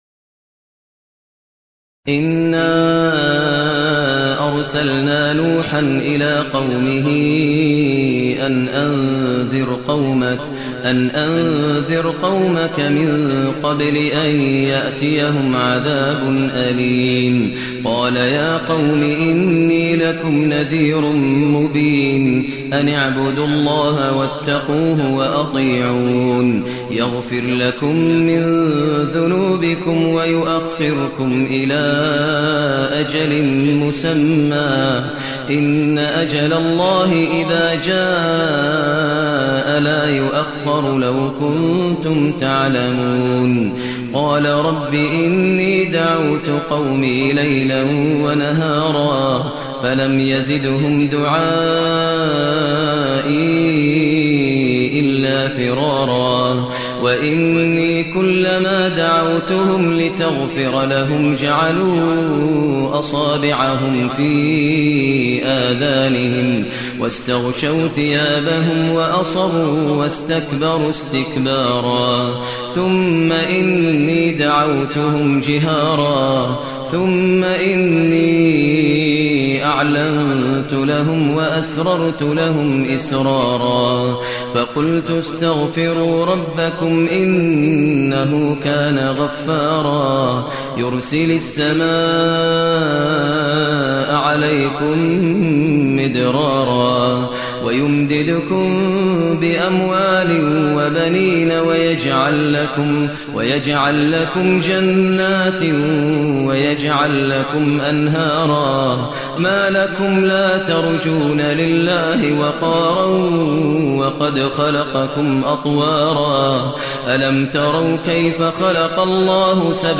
المكان: المسجد النبوي الشيخ: فضيلة الشيخ ماهر المعيقلي فضيلة الشيخ ماهر المعيقلي نوح